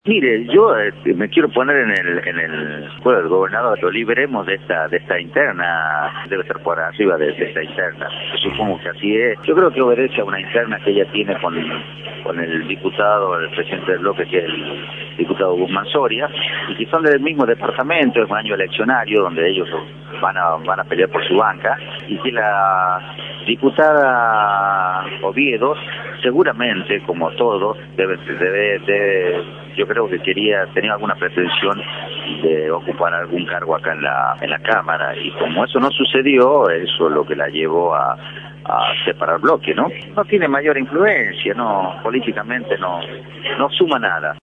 Luis Orquera, diputada por General Lamadrid, por Radio La Red Cristina Saúl, diputada por Rosario Vera Peñaloza, por Radio La Red
“El gobernador gobierna para todos”, destacó en diálogo con Radio La Red.